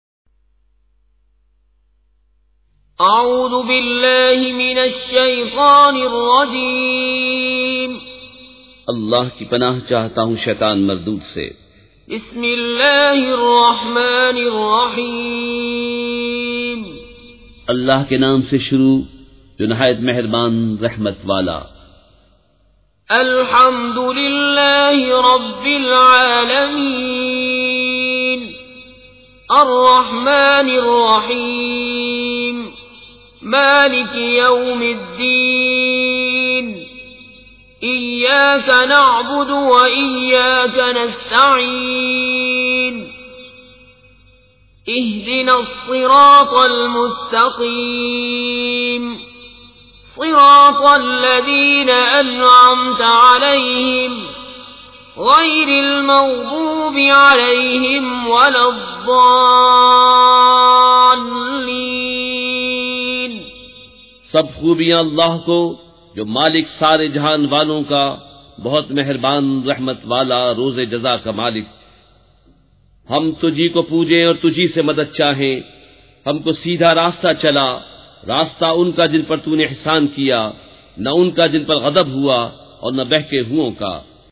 سورۃ الفاتحہ مع ترجمہ کنزالایمان ZiaeTaiba Audio میڈیا کی معلومات نام سورۃ الفاتحہ مع ترجمہ کنزالایمان موضوع تلاوت آواز دیگر زبان عربی کل نتائج 4079 قسم آڈیو ڈاؤن لوڈ MP 3 ڈاؤن لوڈ MP 4 متعلقہ تجویزوآراء
surah-al-fatiha-with-urdu-translation.mp3